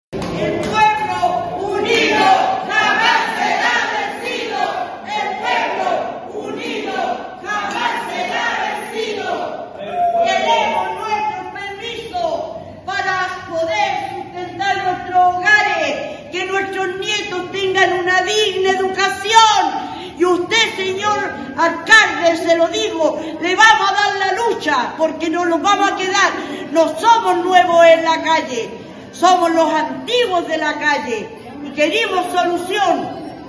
Donde, desde el sindicato de mujeres trabajadoras, Elena Caffarena, se manifestaron a viva voz.
manifestacion-cuna.mp3